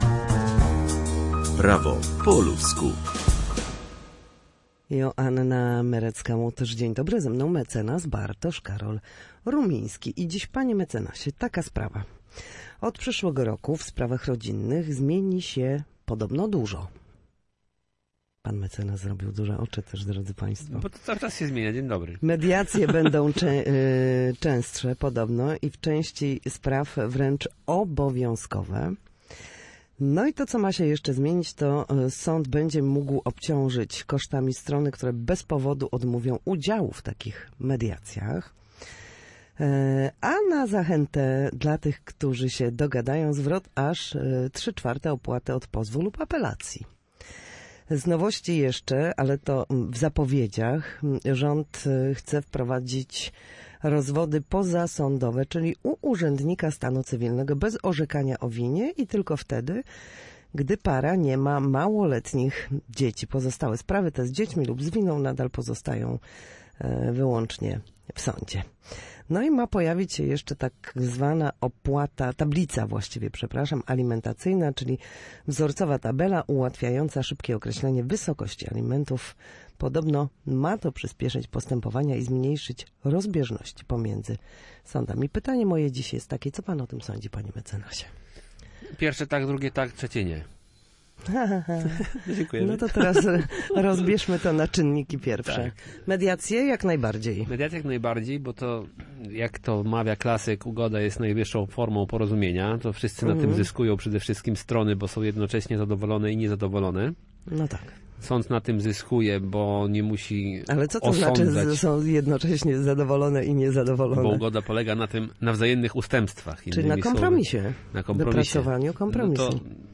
W każdy wtorek, o godzinie 13:40, na antenie Studia Słupsk przybliżamy Państwu meandry prawa.